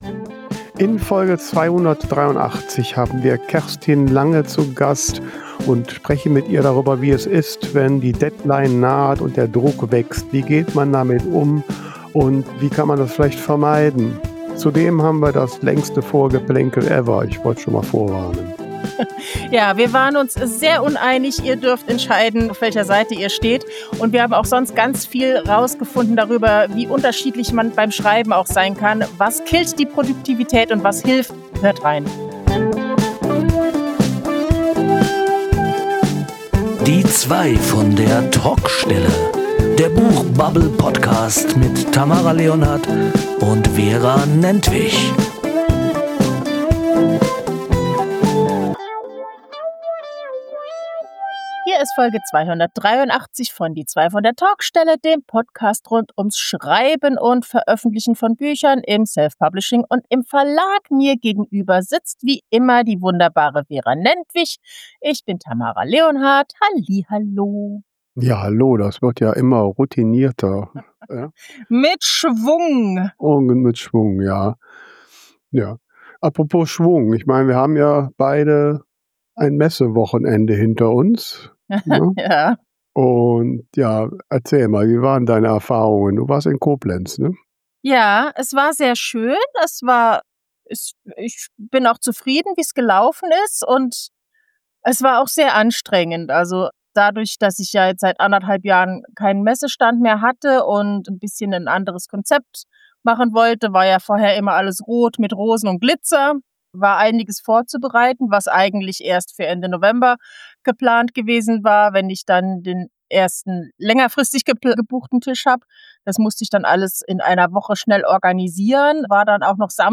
Wie kommt man nach einer anstrengenden Schreibphase wieder in Schwung fürs neue Projekt?Neben all diesen Fragen diskutieren die beiden Hosts außerdem hitzig darüber, ob und wie Besucher:innen auf Buchmessen von Schreibenden mit eigenem Stand angesprochen werden wollen.